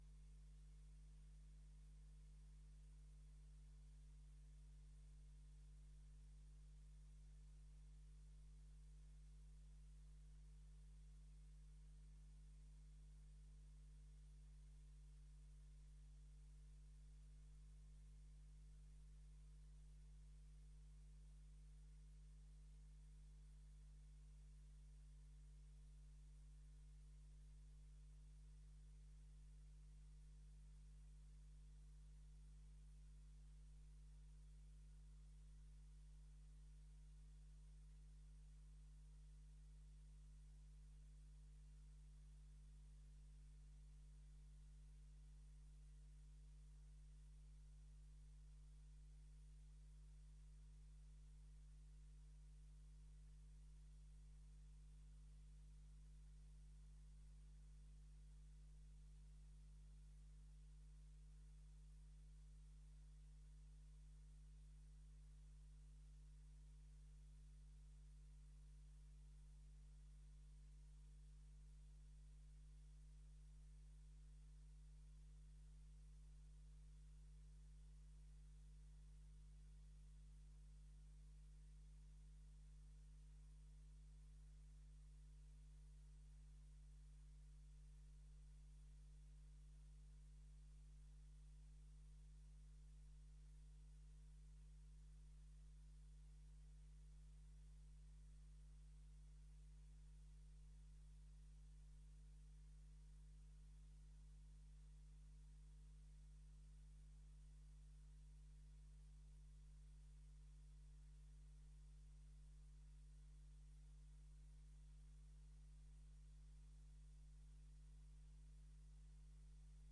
Raadscommissie 12 mei 2025 19:30:00, Gemeente Dalfsen
Locatie: Raadzaal